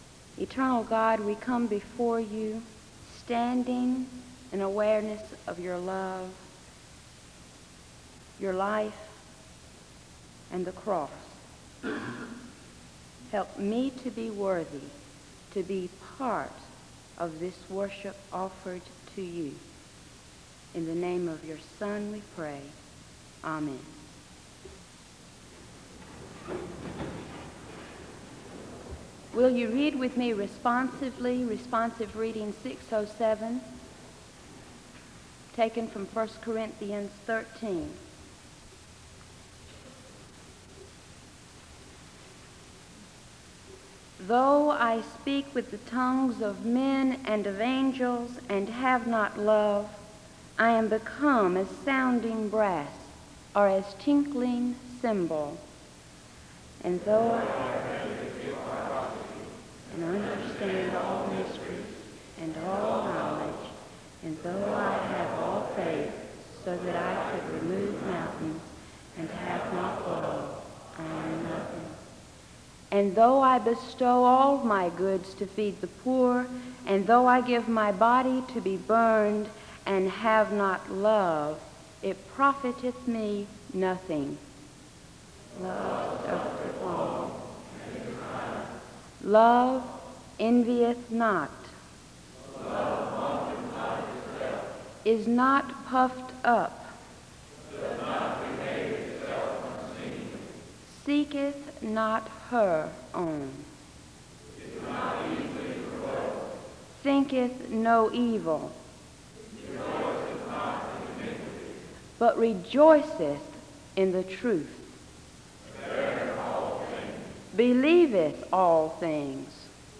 The service begins with a word of prayer and a responsive reading from 1 Corinthians 13 (00:00-03:14). The choir sings a song of worship (03:15-06:22).
SEBTS Chapel and Special Event Recordings SEBTS Chapel and Special Event Recordings